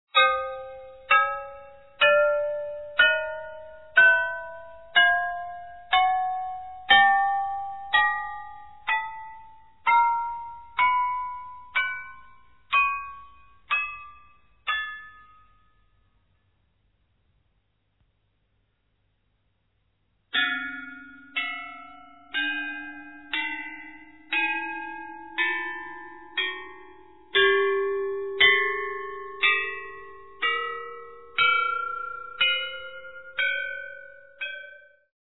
Fascinating and diverse Korean traditional orchestral music.
Recorded in Seoul, Korea.